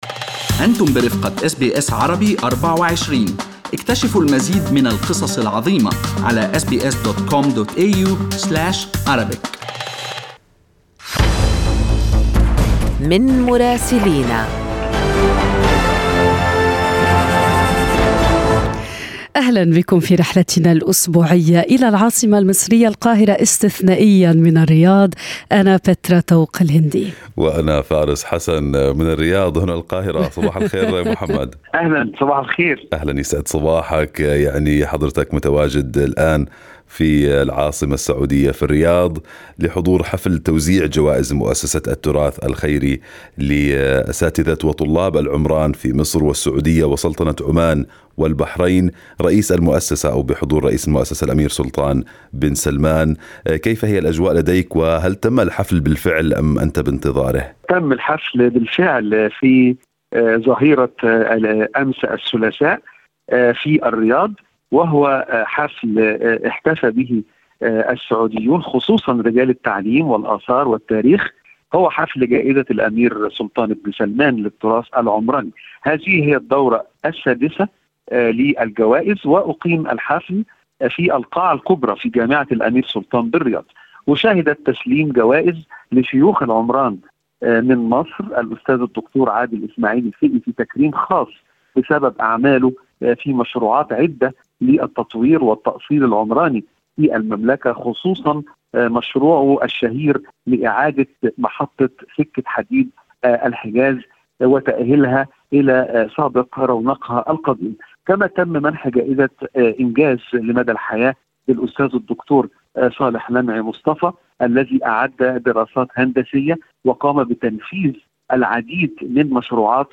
يمكنكم الاستماع إلى تقرير مراسلنا بالضغط على التسجيل الصوتي أعلاه.